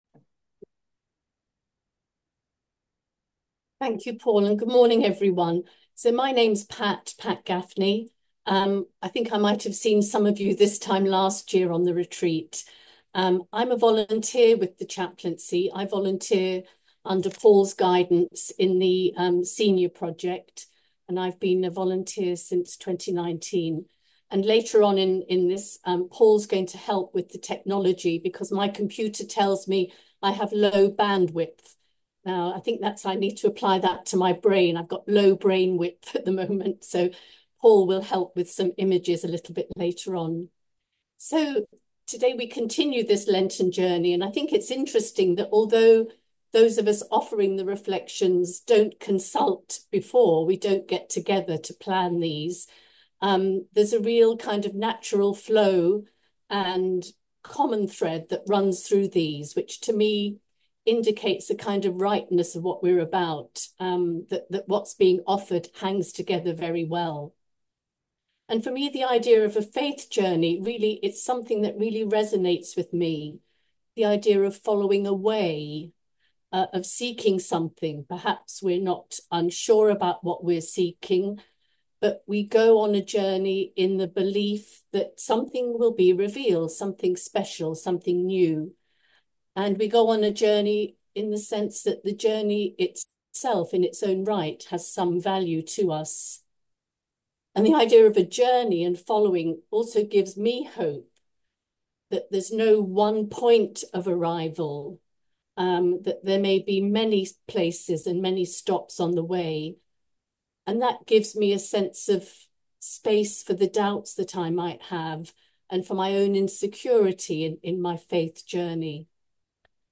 Third Lenten Reflection - 12 March 2025March 13, 2025 Faith